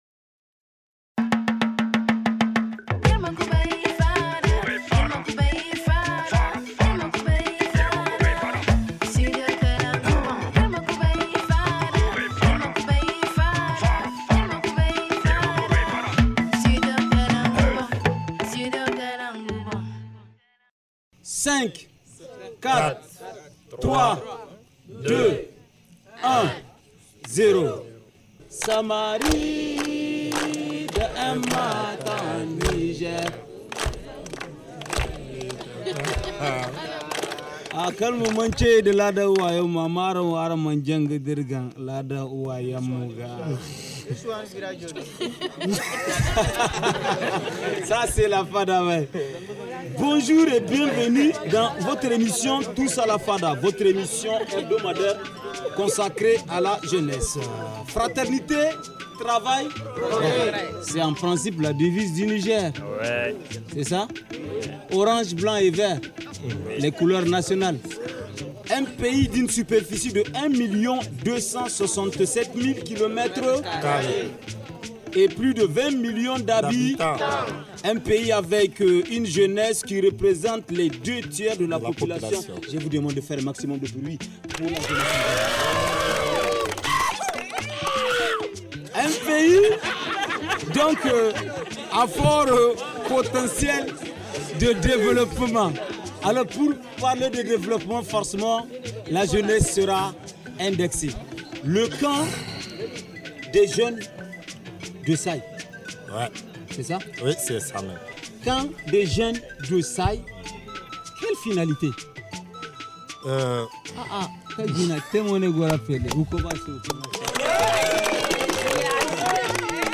Ce dimanche, notre FADA s’est transportée dans la localité de Say pour nous parler du sixième camp des jeunes. Placé sur le thème : Jeunesse responsable, levier des politiques locales et de développement ; ce camp a réuni plusieurs jeunes hommes et femmes.